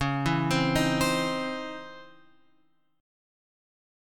C#mM9 chord {9 7 10 8 x 8} chord